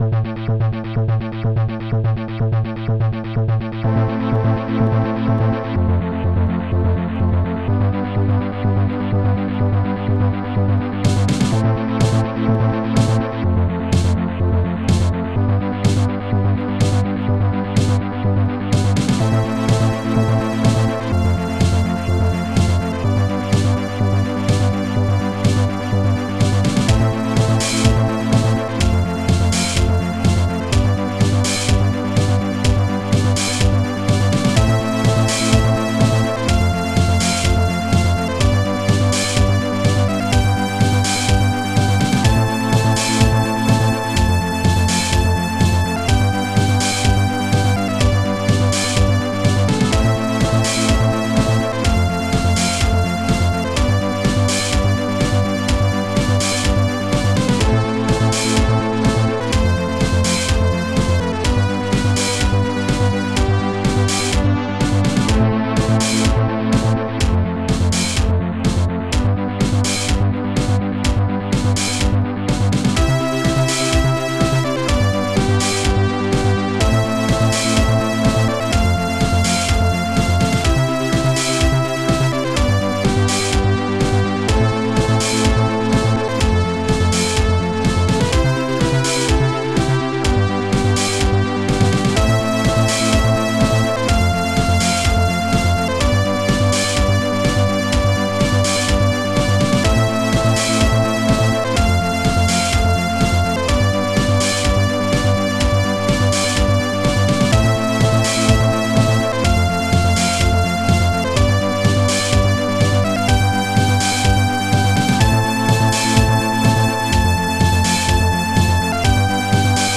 ST-05:bassdrum.c
ST-05:snare.uncle
ST-05:lead.junobrass
ST-05:hihat.open2